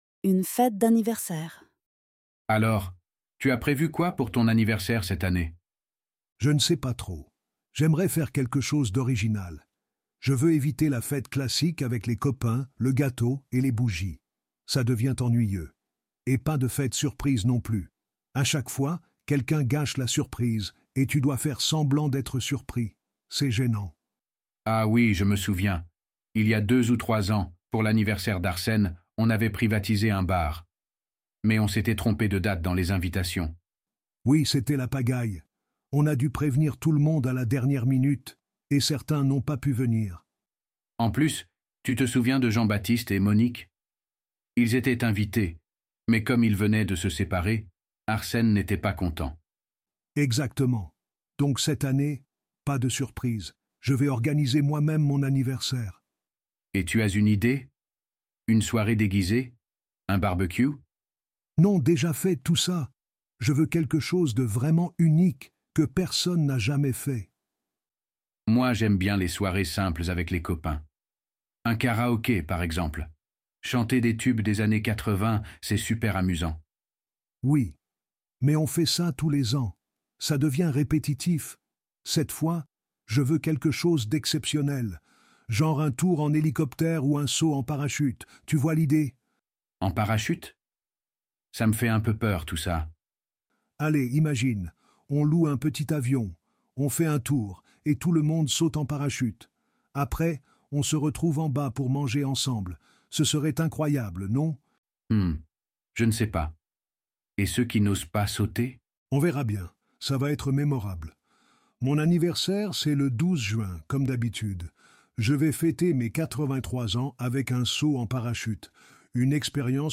Dialogues en Français